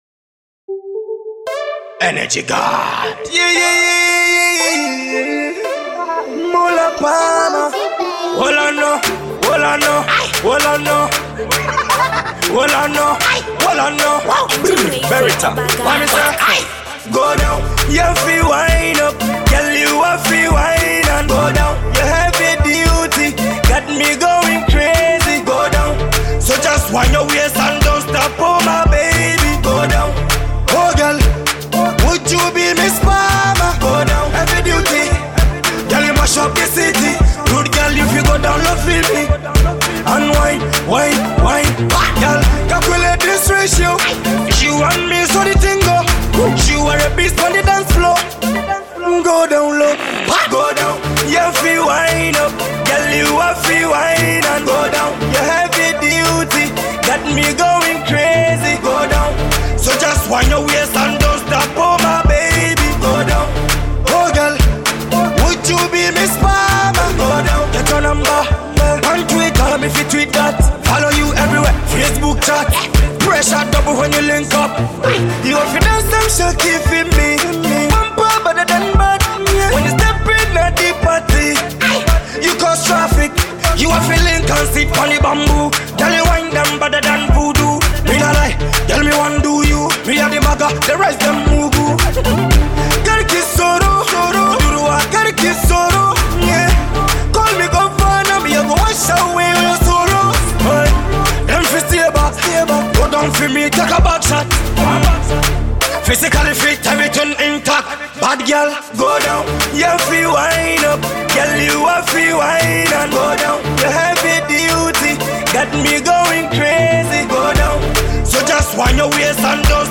Hot afro-dancehall jam